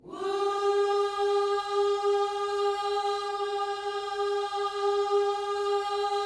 WHOO G#4B.wav